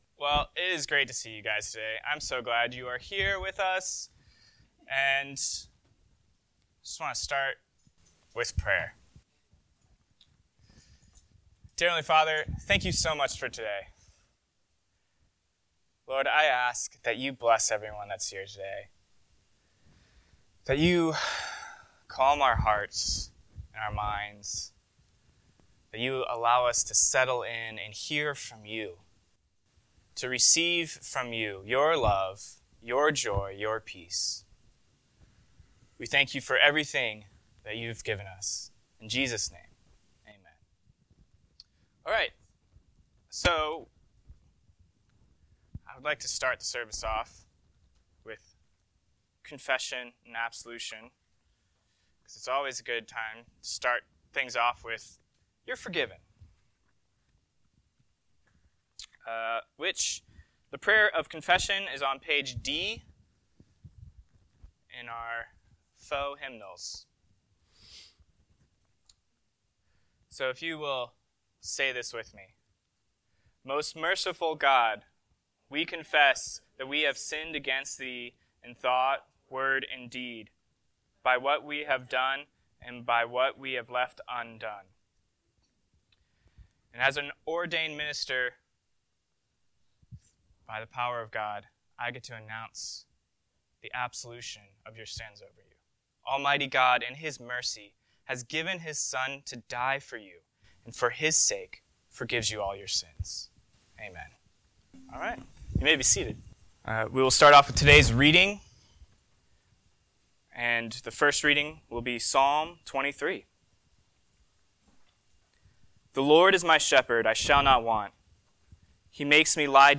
NCCO Sermons